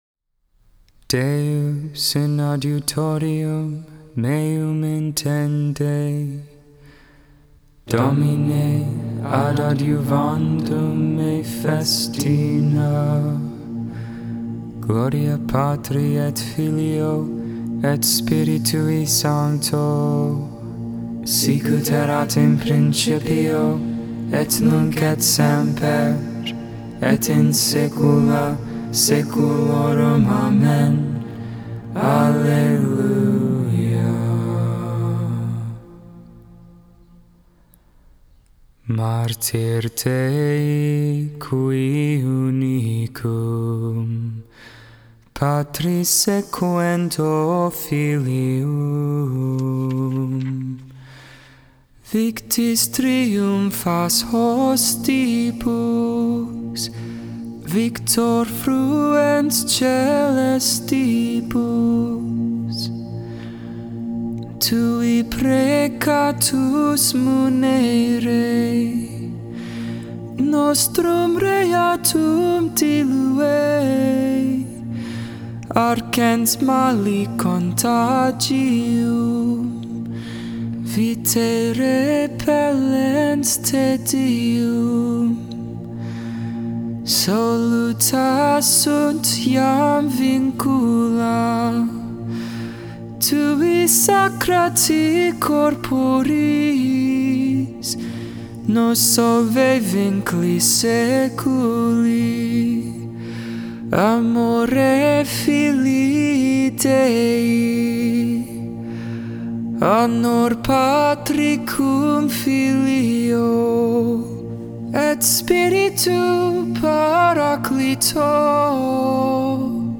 6.28.21 Lauds, Monday Morning Prayer
Deus in Adjutorium Hymn: "Martyr Dei Qui Unicum" Psalm 5v2-10, 12-13 Canticle: 1 Chronicles 29v10-13 Psalm 29 Reading: 2 Corinthians 1v3-5 Responsory: The Lord is my strength, and I shall sing his praise.